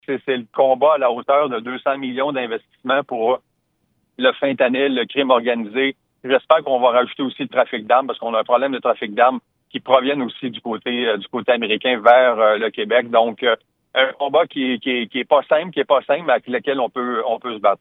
En entrevue sur nos ondes mardi matin, le député de Granby, François Bonnardel, a incité les entreprises et sociétés d’ici de se mettre en mode diversification des marchés pour leur modèle d’affaires.